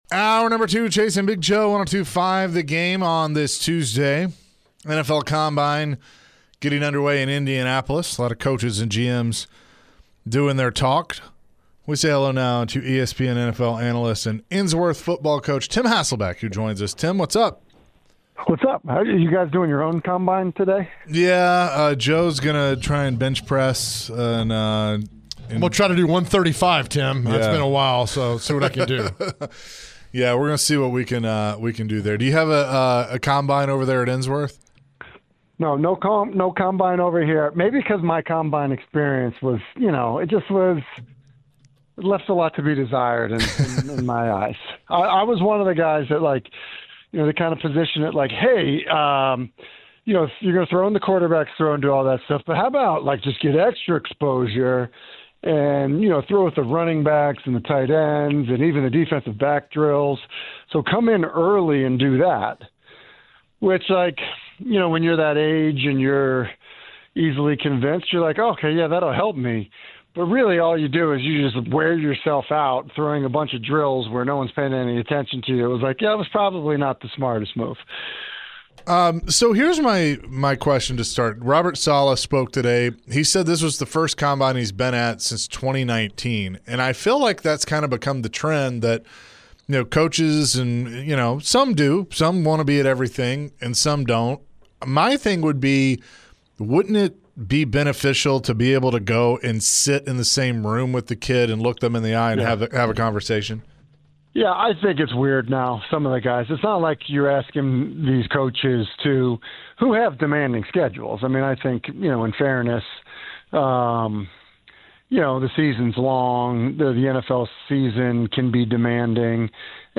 The guys chatted with ESPN NFL Analyst Tim Hasselbeck. Tim shared his thoughts on Robert Saleh and his time at the NFL combine. Later in the conversation, Tim was asked about the NFL and if it's lacking QB talent.